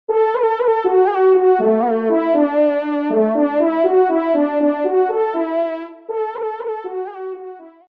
FANFARE
Localisation : Somme